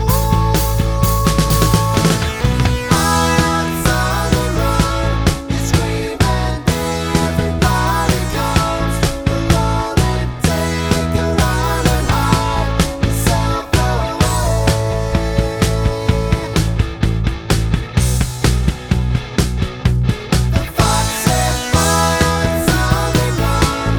no Backing Vocals Glam Rock 3:25 Buy £1.50